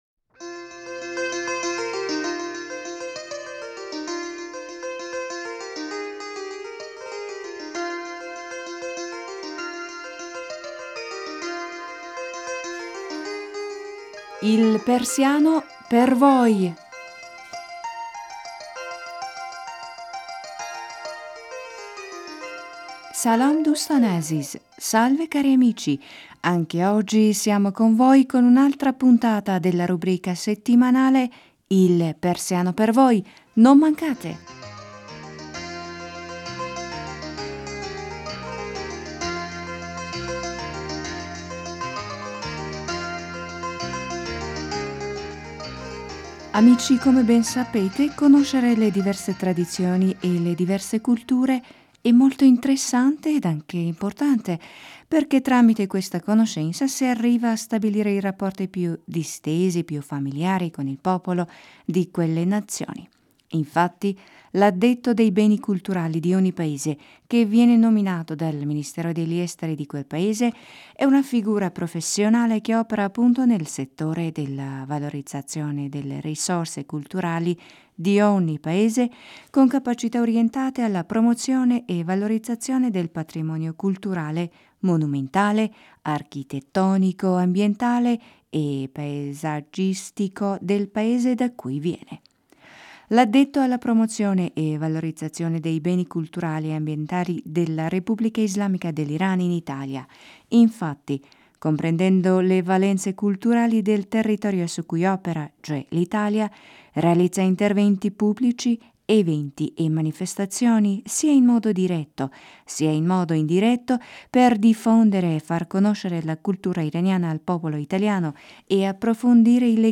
Una di queste iniziative culturali è quella di organizzare dei corsi della lingua Farsi in Italia. Amici oggi vogliamo ascoltare insieme a voi la conversazione telefonica tra Paolo e Francesco, uno dei suoi amici che vive a Roma.